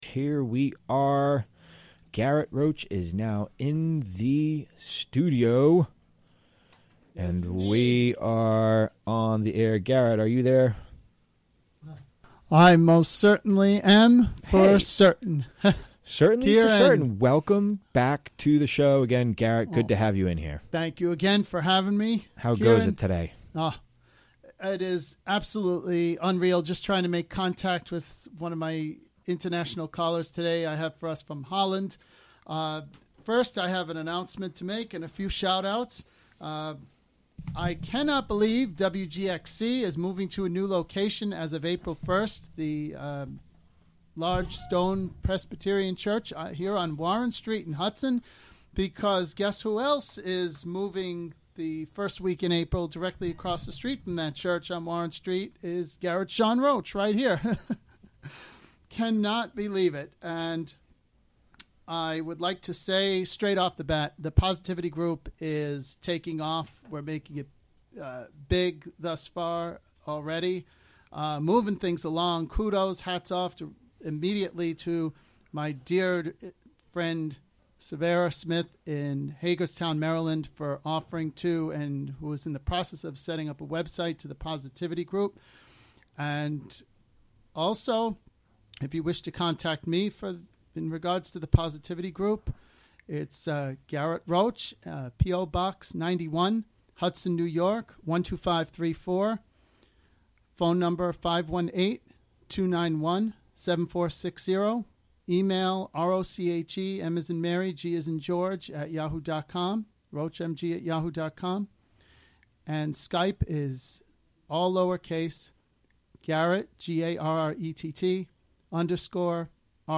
Interviewed
Recorded live on the WGXC Afternoon show on March 5, 2018.